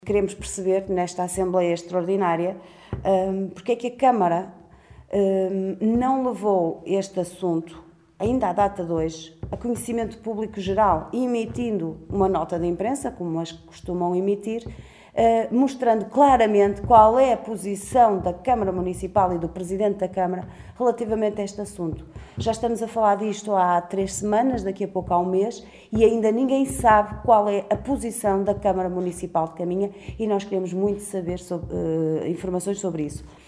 Liliana Silva na Conferência de imprensa desta manhã convocada pela OCP.